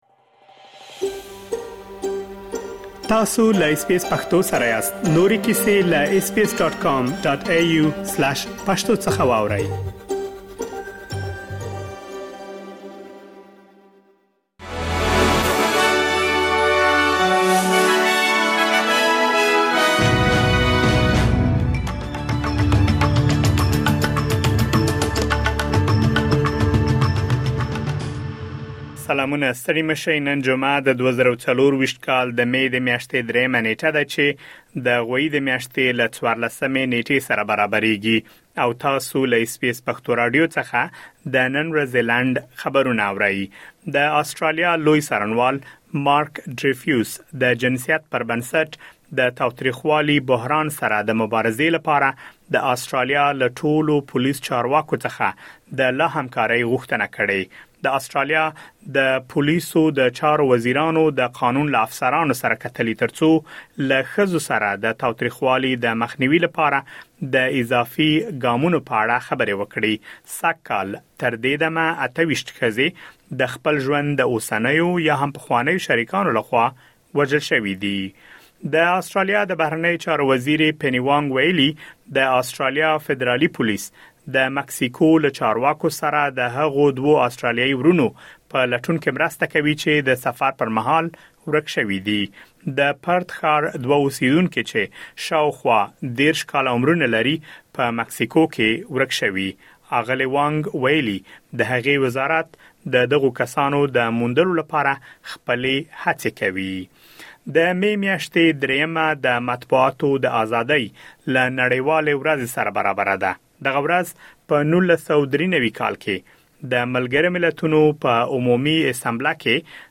د اس بي اس پښتو راډیو د نن ورځې لنډ خبرونه|۳ مې ۲۰۲۴